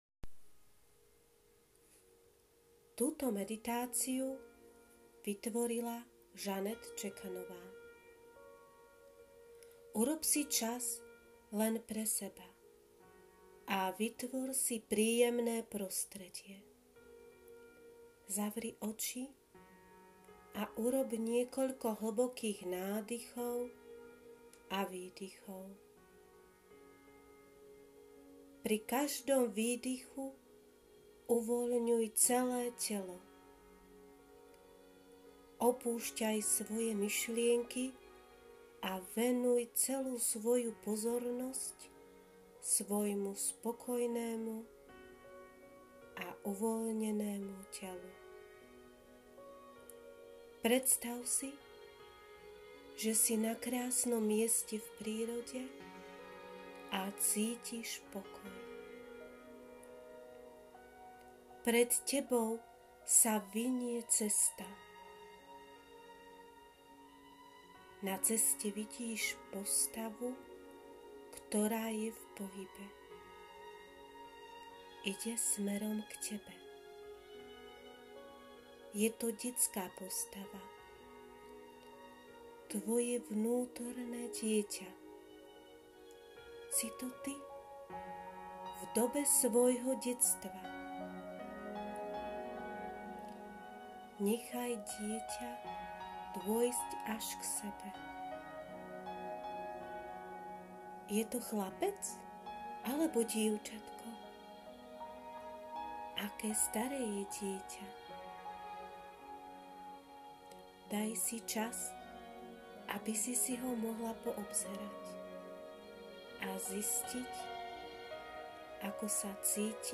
Meditacia-Stretnutie-s-vnutornym-dietatom.mp3